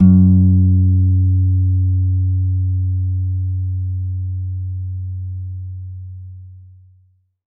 52-str07-abass-f#2.aif